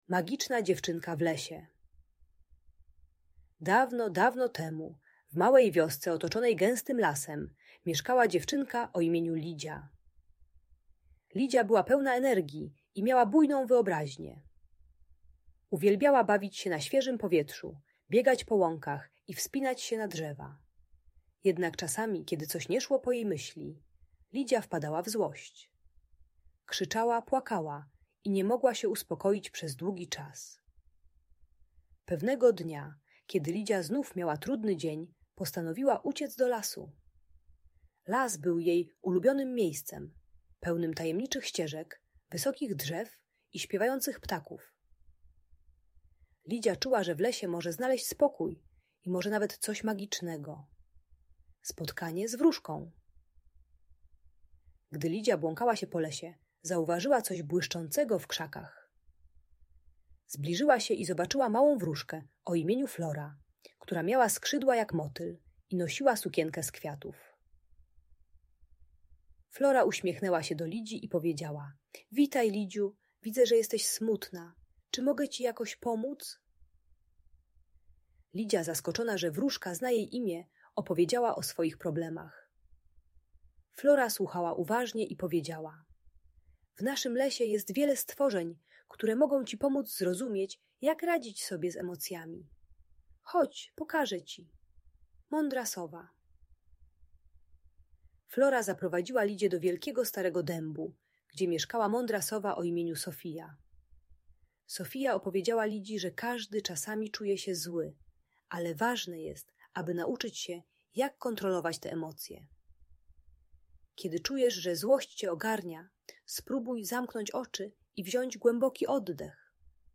Magic Bajka: Magiczna Dziewczynka w Lesie - Audiobajka